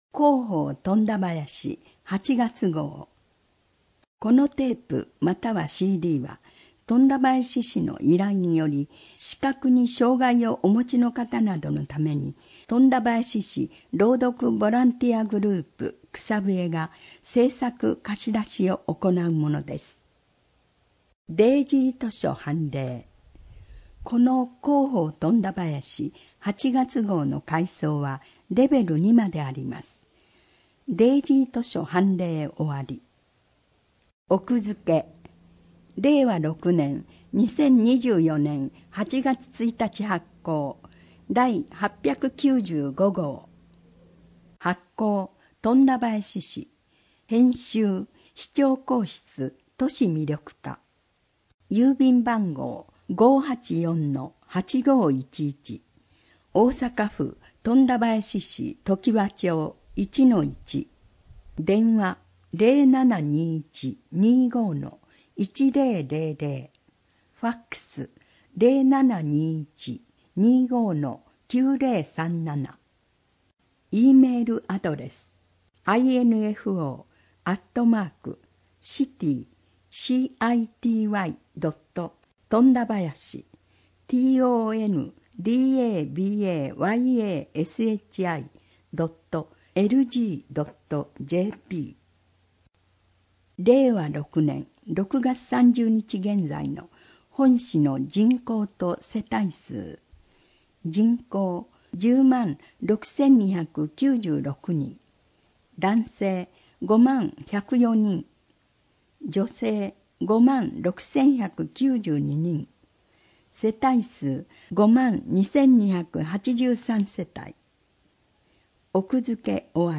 この音声は、本市の依頼により富田林市朗読ボランティアグループ「くさぶえ」が視覚に障がいをお持ちの人などのために製作しているものです（図やイラストなど一部の情報を除く）。